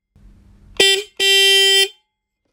400Hz-9
400Hz-9.m4a